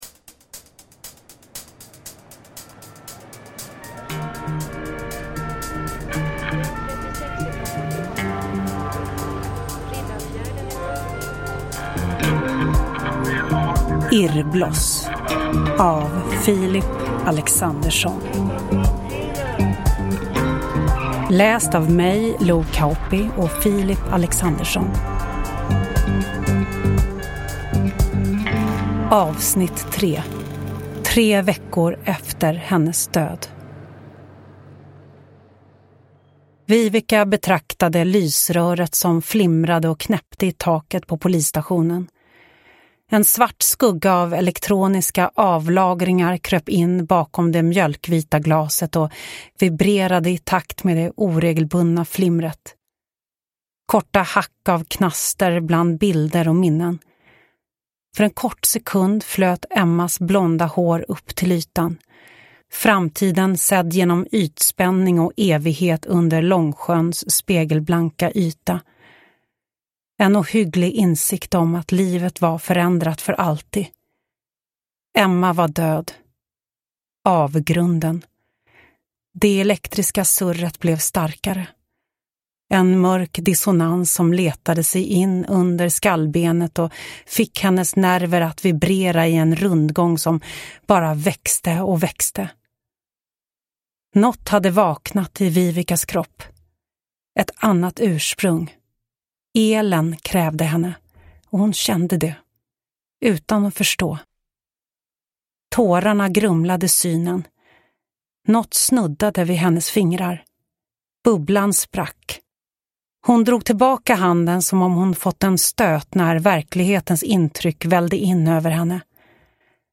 Hidden S1A3 Irrbloss : Tre veckor efter hennes död – Ljudbok – Laddas ner